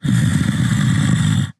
Divergent / mods / Soundscape Overhaul / gamedata / sounds / monsters / fracture / idle_1.ogg